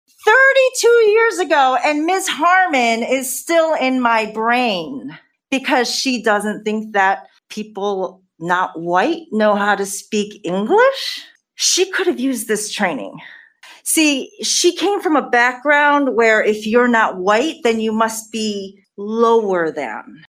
A packed crowd during Wednesday’s USD 383 meeting for public comment in regards to culturally responsive teaching and learning training.